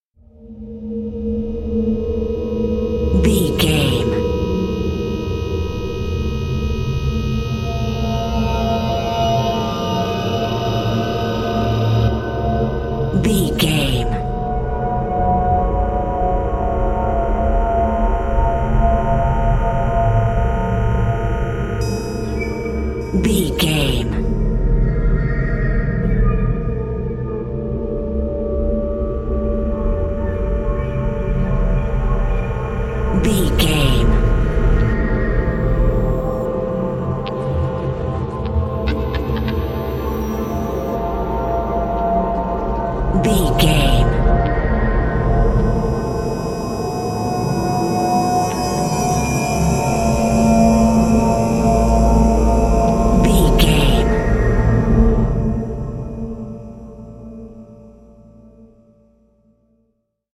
Thriller
Ionian/Major
E♭
Slow
synthesiser